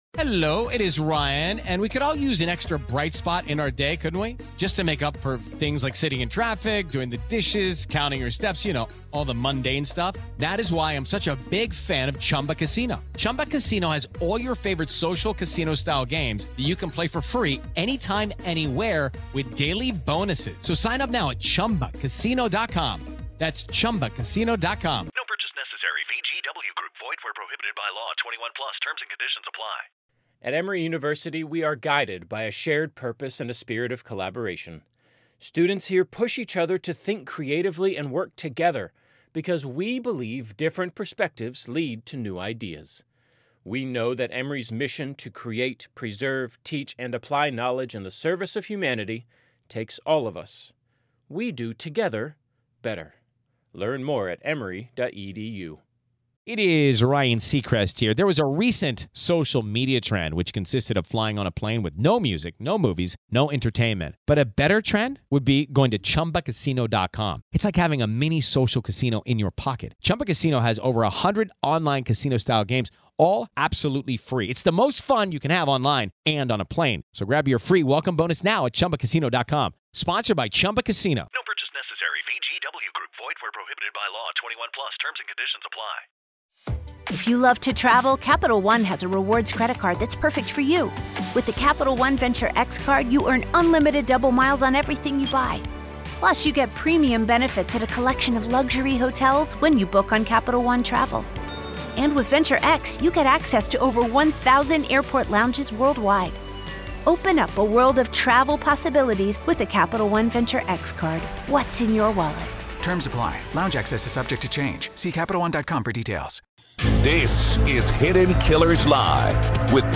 Daily True Crime News & Interviews